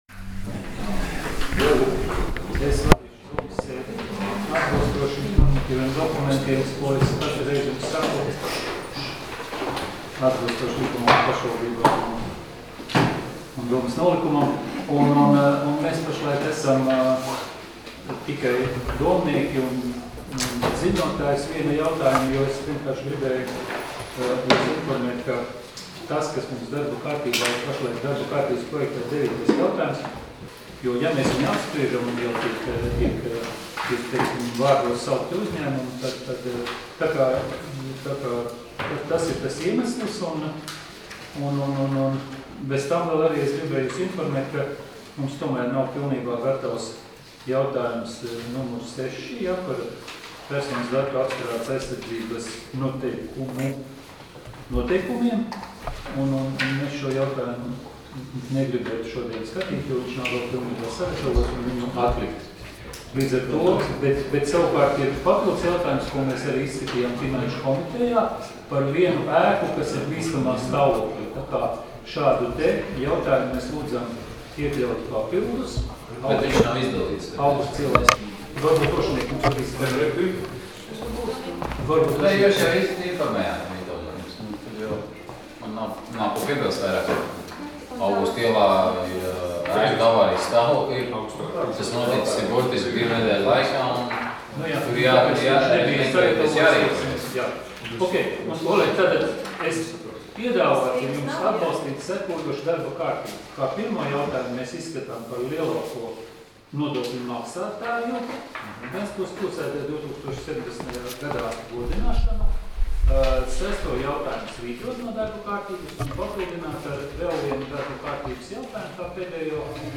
Domes sēdes 08.06.2018. audioieraksts